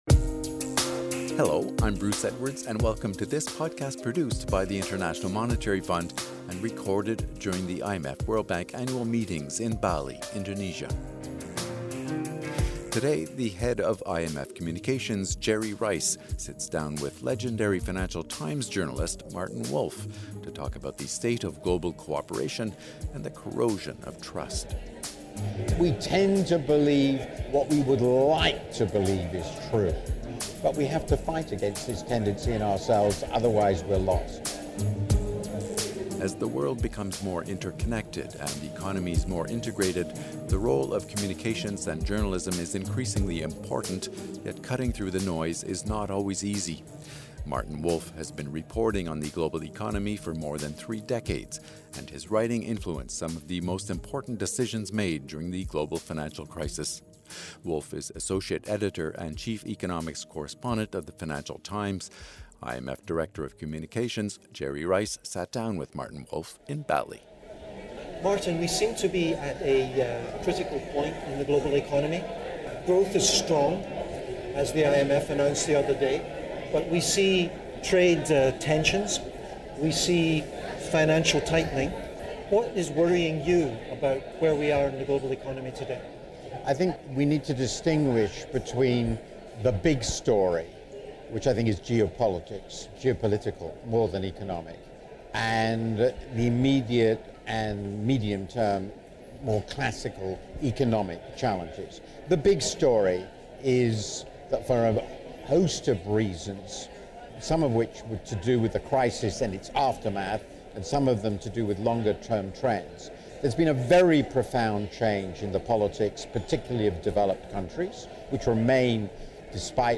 Their conversation was recorded during the IMF-World Bank Annual Meetings in Bali, Indonesia.